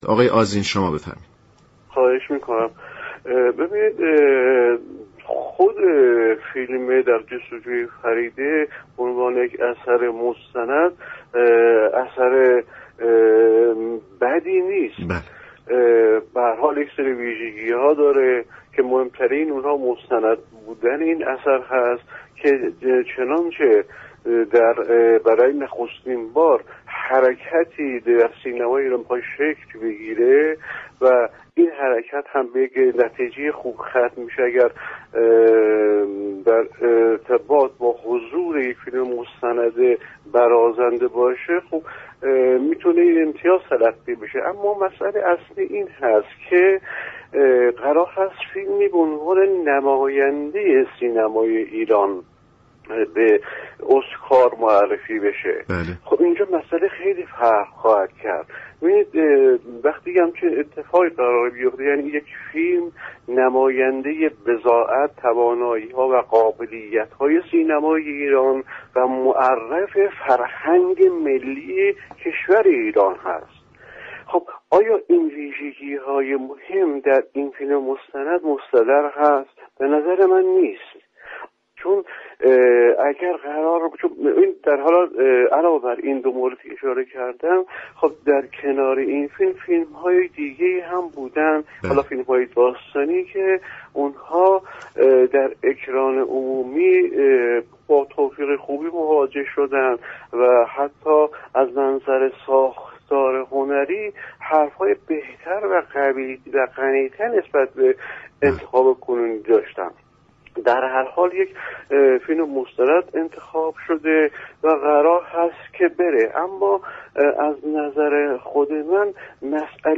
گفت و گو
به روی خط رادیو ایران آمد